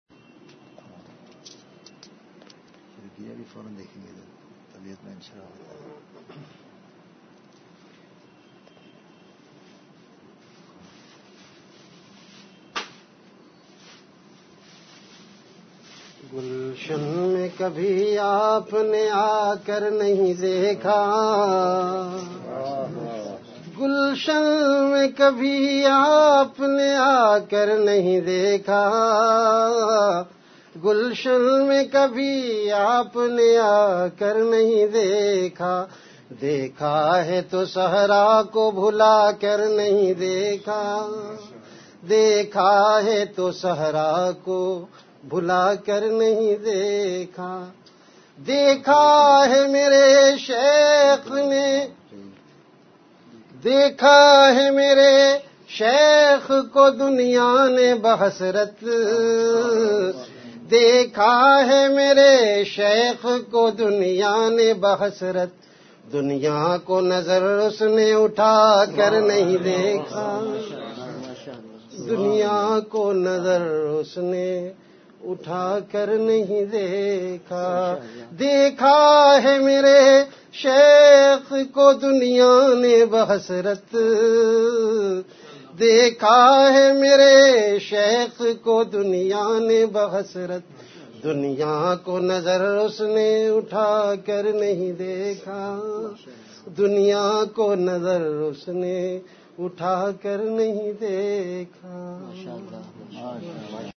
CategoryAshaar
VenueKhanqah Imdadia Ashrafia
Event / TimeAfter Magrib Prayer